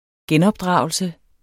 Udtale [ ˈgεnʌbˌdʁɑˀwəlsə ]